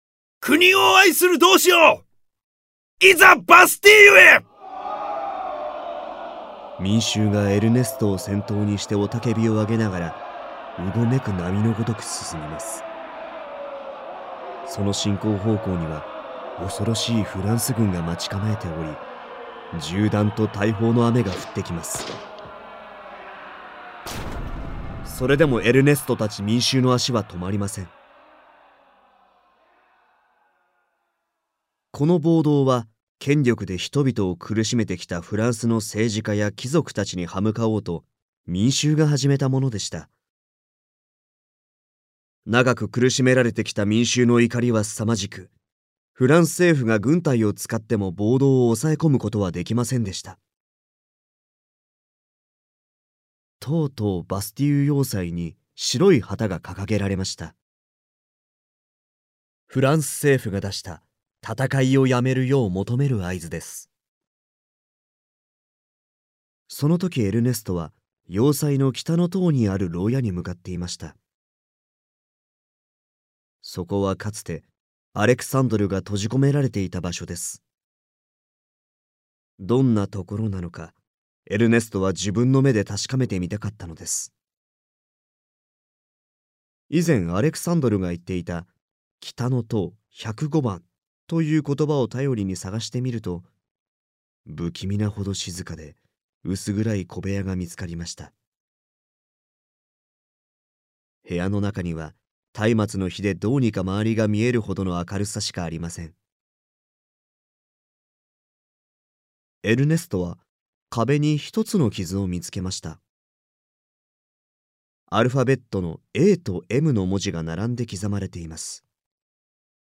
[オーディオブック] 二都物語（こどものための聴く名作44）